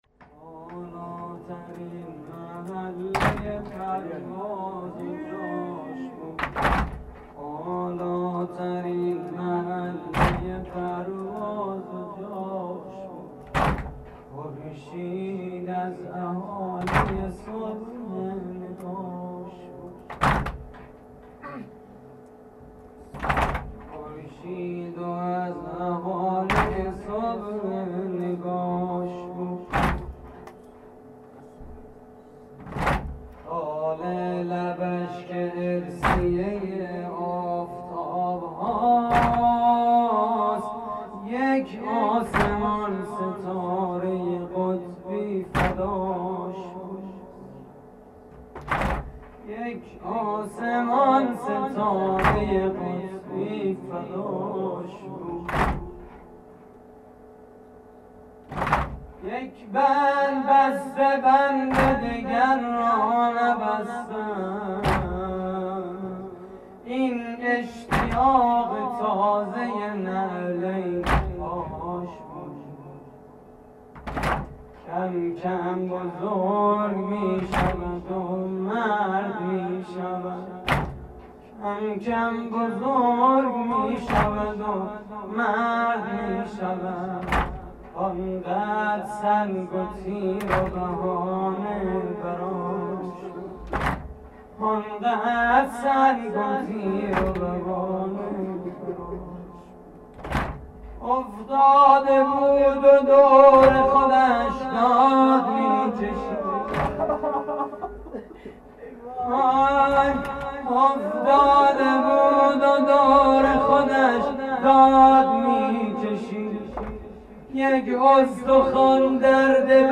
مراسم عزاداری شب ششم محرم 1432